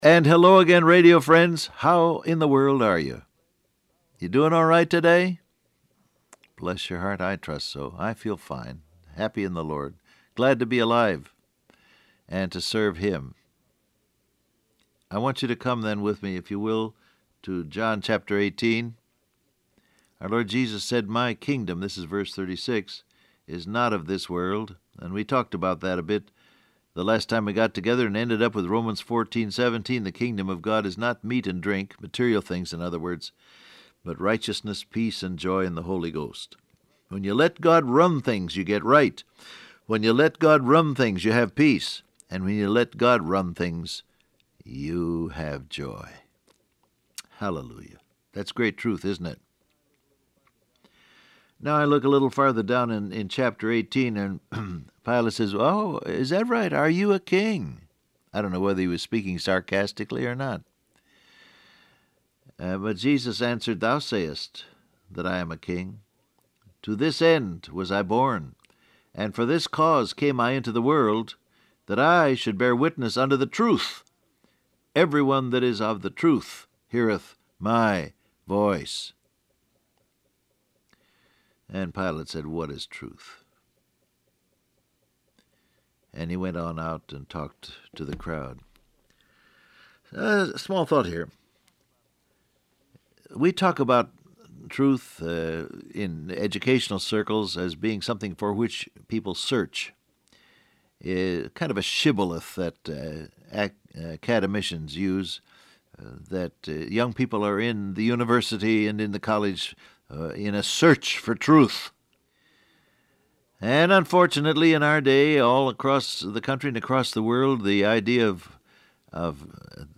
Download Audio Print Broadcast #6966 Scripture: John 18:36 , John 19:1-8 Topics: Facts , Obedience , Truth , King , Crucify Transcript Facebook Twitter WhatsApp And hello again, radio friends.